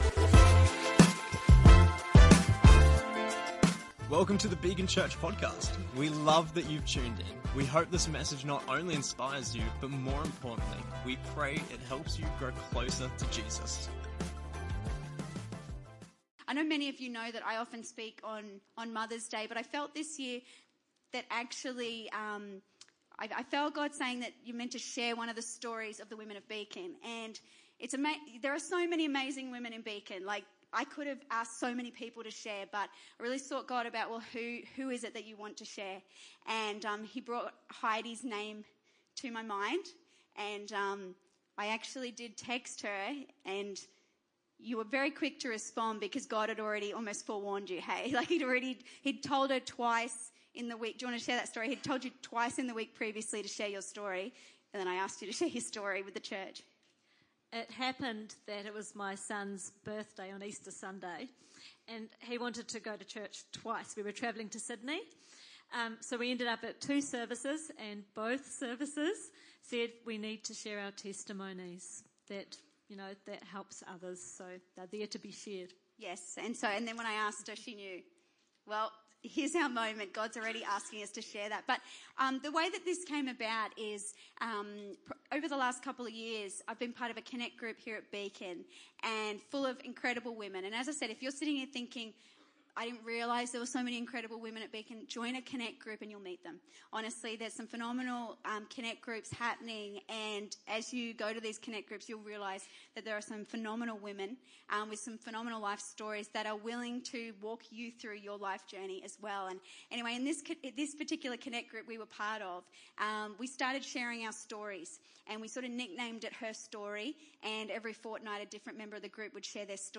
Episode 63: Mothers' Day Special - Interview